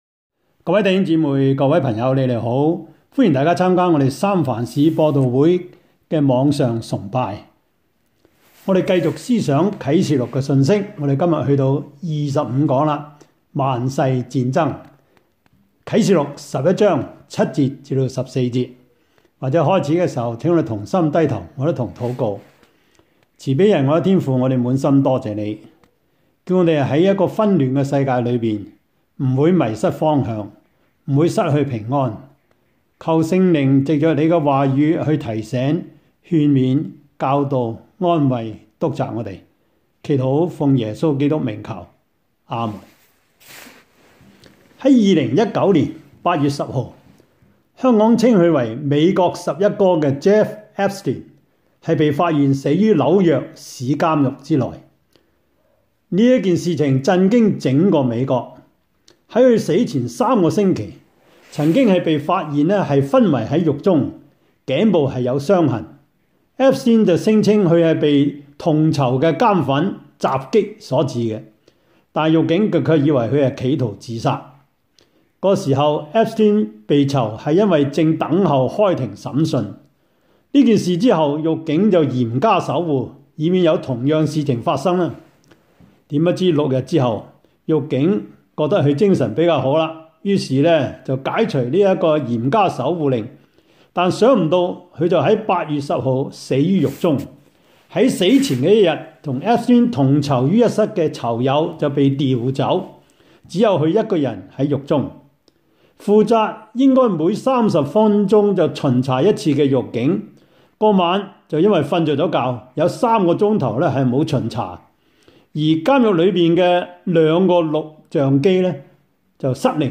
Service Type: 主日崇拜
Topics: 主日證道 « 門徒本色 5: 謙卑 十二籃子的碎餅碎魚是怎樣來的?